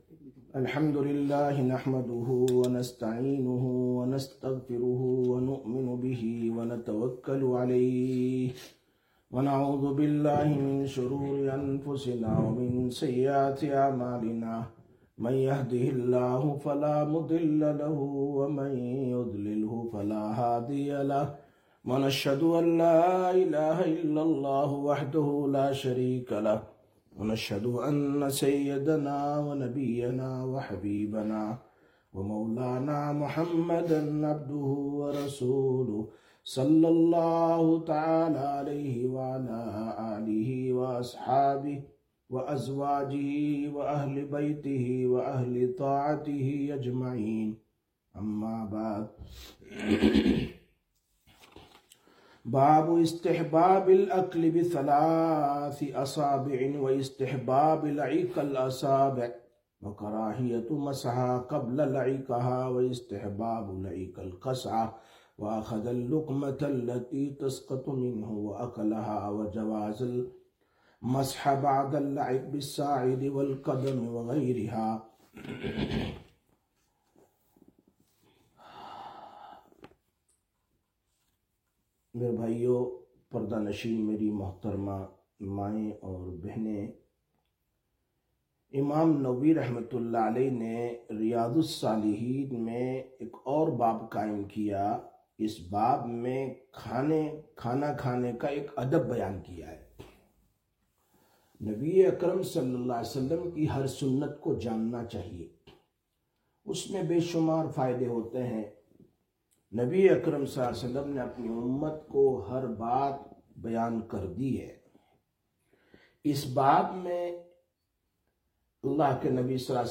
30/07/2025 Sisters Bayan, Masjid Quba